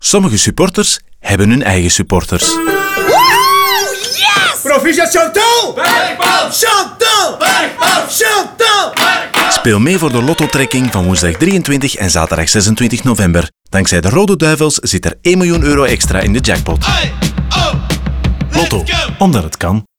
Ook in radio leeft de campagne waar typische stadiontaferelen hun weg hebben gevonden naar… de krantenwinkel.
Radiospot 1.wav